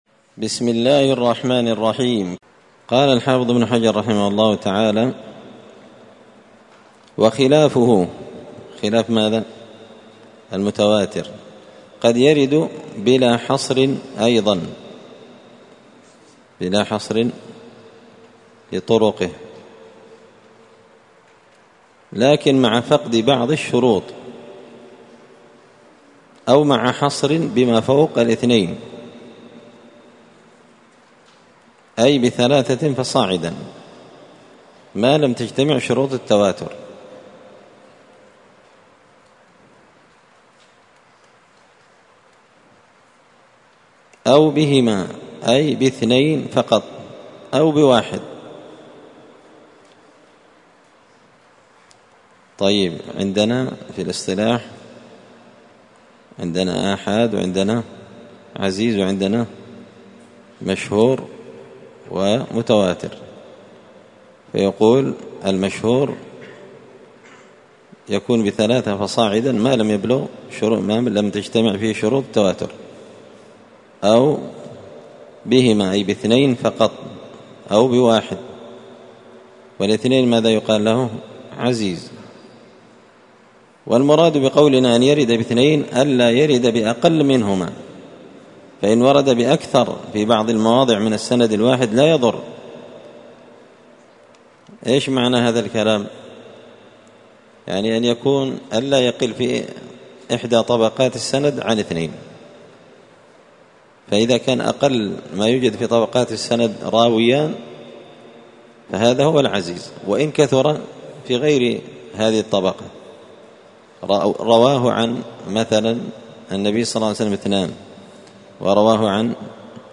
تعليق وتدريس الشيخ الفاضل:
4الدرس-الرابع-من-كتاب-نزهة-النظر-للحافظ-ابن-حجر.mp3